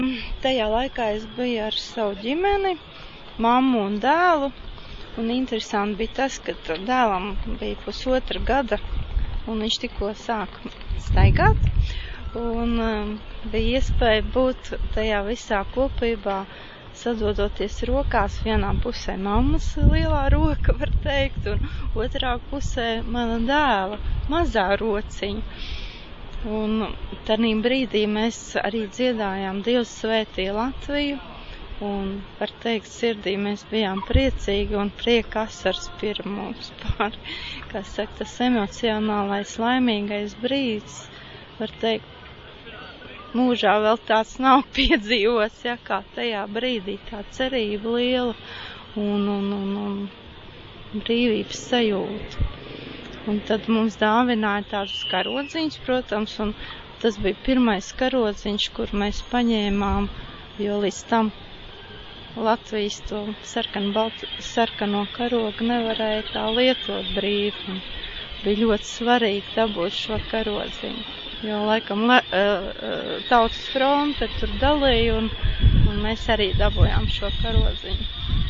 Atmiņu stāsts ierakstīts Eiropas digitālās bibliotēkas "Europeana" un Latvijas Nacionālās bibliotēkas organizētajās Baltijas ceļa atceres dienās, kas notika 2013. gada 23. un 24. augustā Rīgā, Esplanādē.